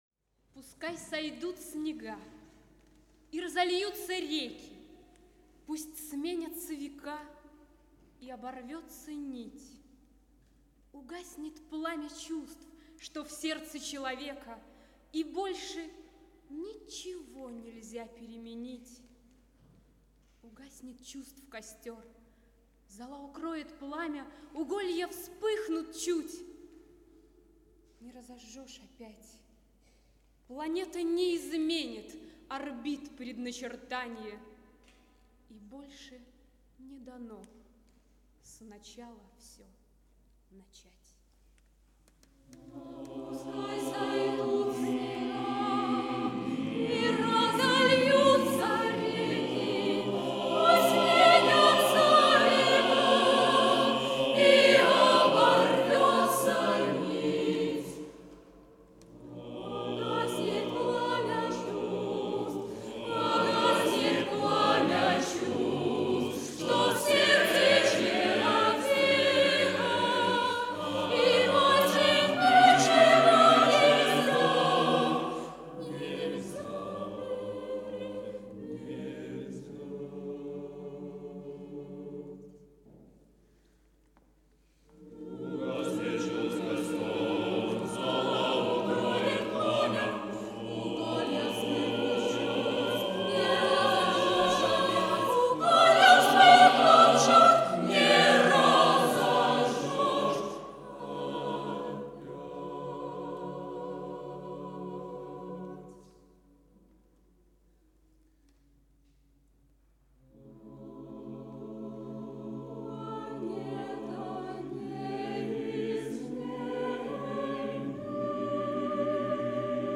МУЗЫКА ДЛЯ СМЕШАННОГО ХОРА: